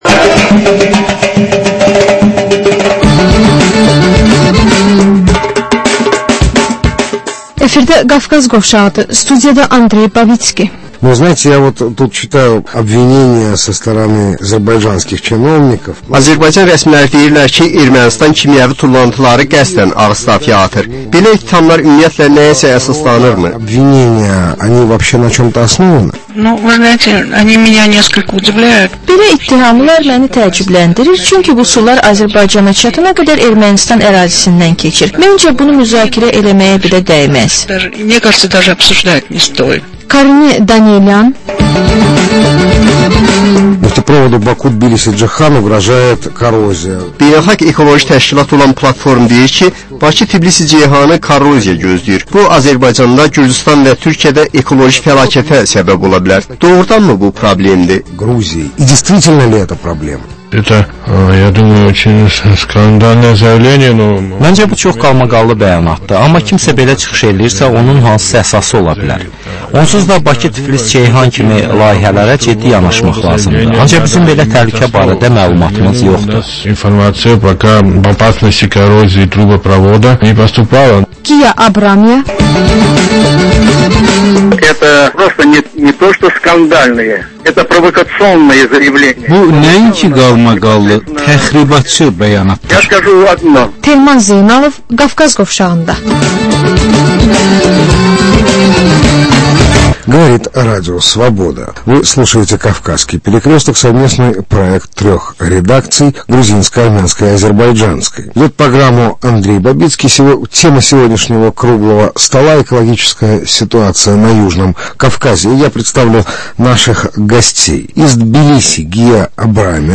Azərbaycan, Gürcüstan və Ermənistandan reportajlar